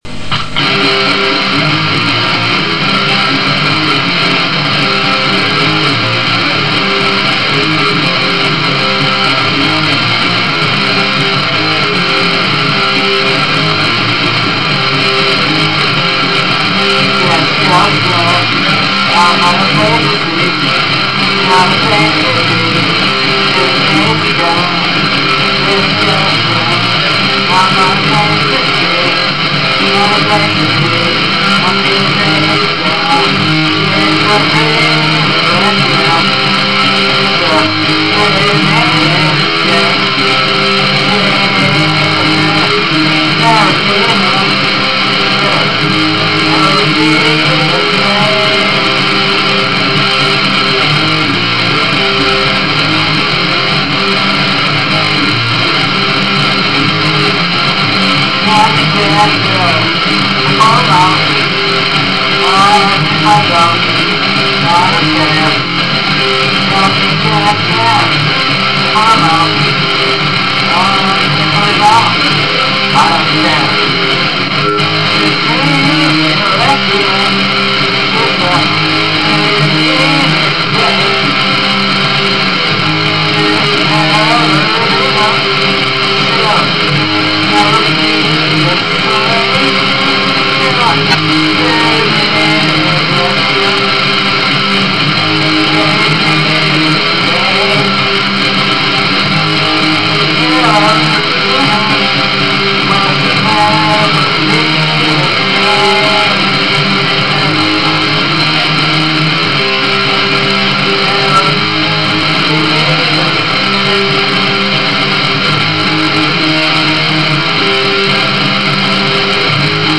Some really noizy and cool wave-files done by me (in first take with fuck-ups and whatevers included):
ELECTRIC
Well, it could be better, but, you have to bare with this version for now...*L*...Noizy...